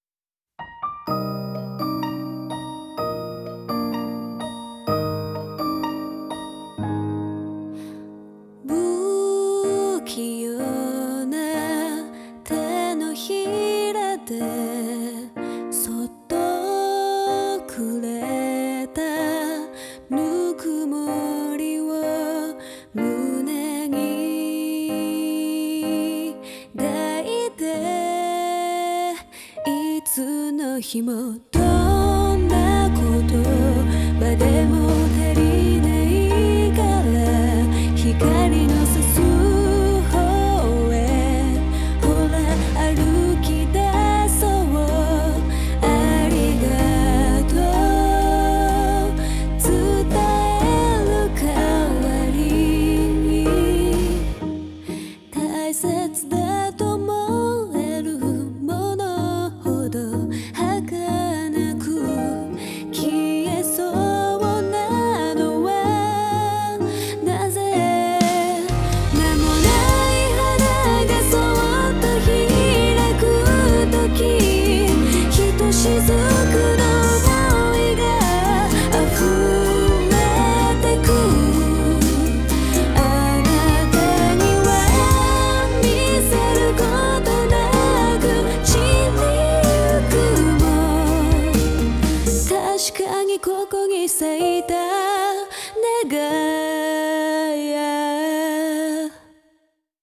Однако девочка предпочитает замедленную версию: